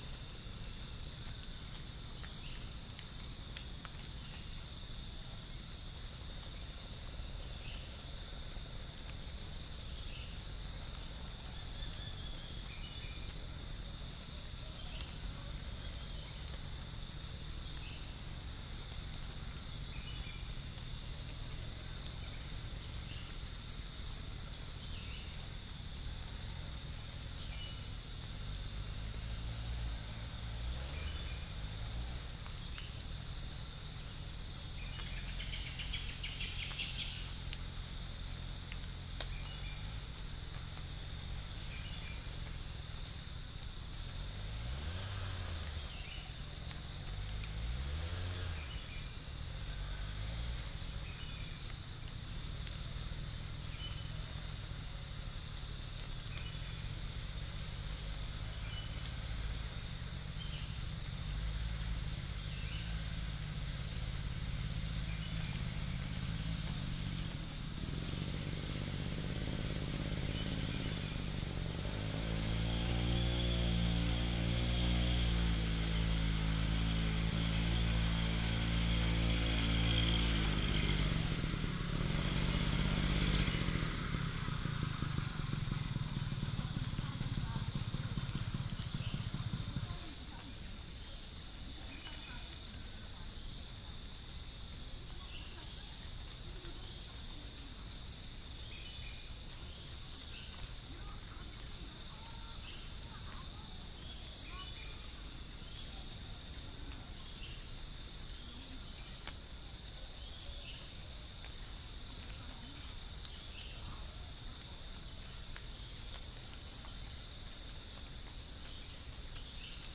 Upland plots dry season 2013
Picus puniceus
Mixornis gularis
2 - insect
Chloropsis moluccensis